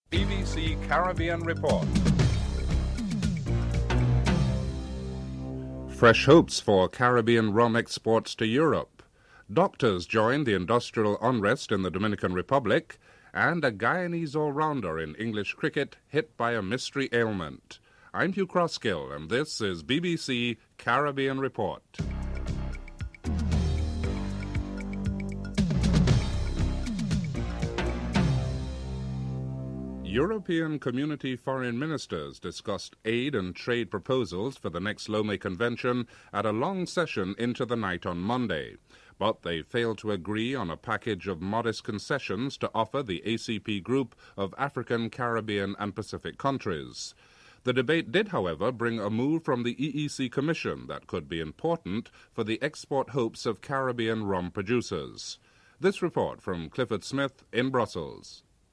1. Headlines (00:00-00:21)
4. Financial News (07:03-08:06)